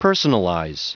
Prononciation du mot personalize en anglais (fichier audio)
Prononciation du mot : personalize